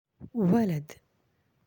(walad)
How to say Boy in Arabic
walad.aac